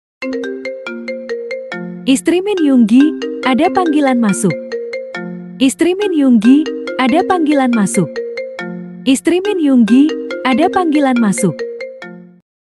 Genre: Nada dering panggilan